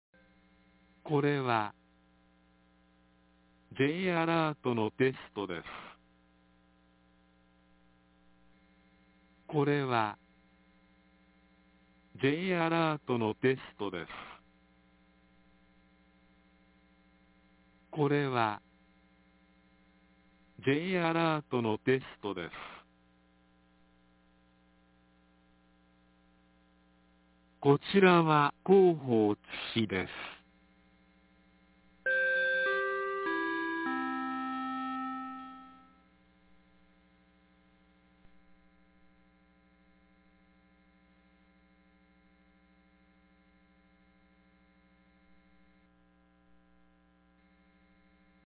2024年12月25日 12時44分に、津市より高野尾、豊が丘、大里、白塚、栗真、一身田、北立誠、南立誠、津西、安東、櫛形、片田、神戸、新町、養正、敬和、育生、修成、藤水、南が丘、高茶屋、雲出、誠之、成美、立成、桃園、戸木、栗葉、榊原、豊津、上野、黒田、千里ヶ丘、椋本、明、安西、雲林院、河内、高宮、長野、辰水、草生、村主、安濃、明合、香良洲、大井、波瀬、川合、高岡、家城、川口、大三、倭、八ツ山、竹原、八知、太郎生、伊勢地、八幡、多気、下之川へ放送がありました。
放送音声